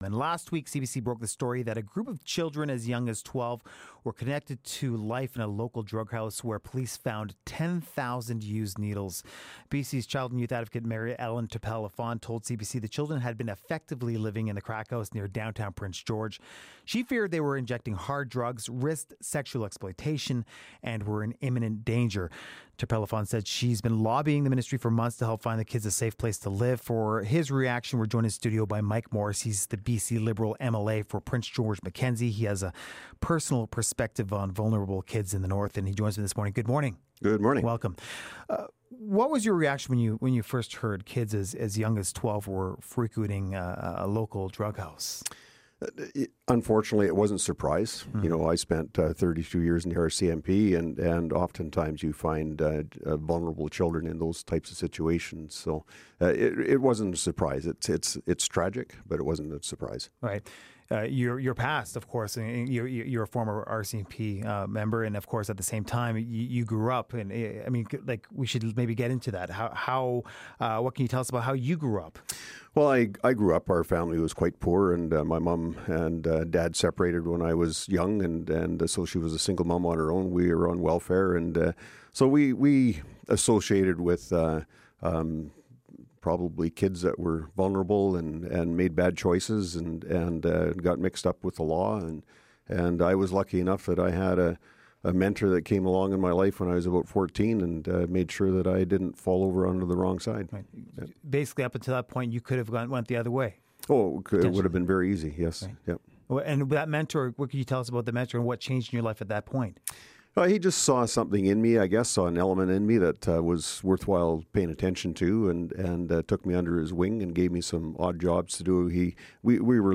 Following reports of youth, some as young as twelve, frequenting a known drug house in Prince George, we speak to MLA and former RCMP officer Mike Morris about the role of the police and the province.